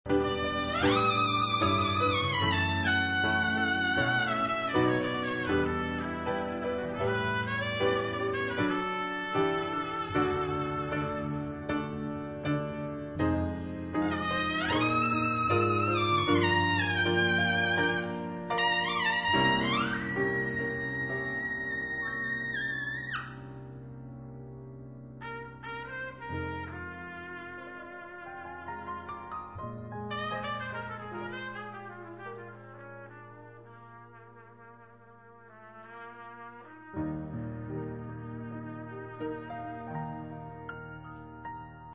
Trumpet Sounds of My Students: